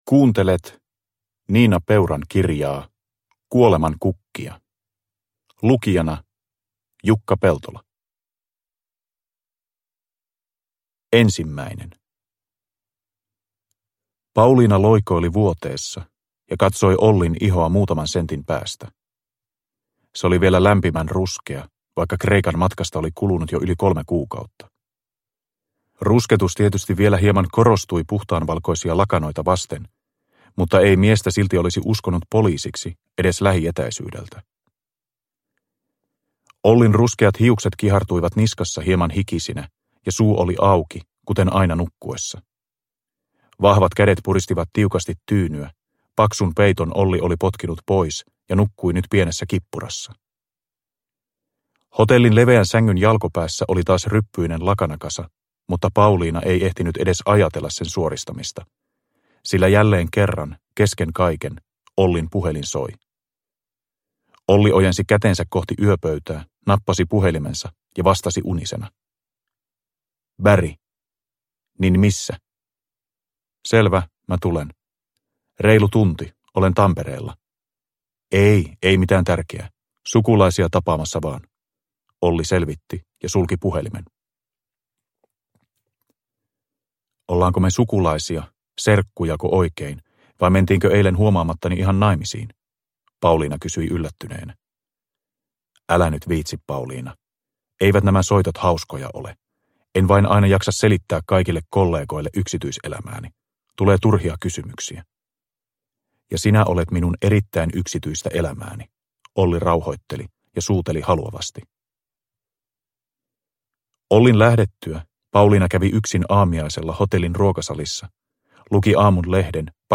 Kuolemankukkia – Ljudbok – Laddas ner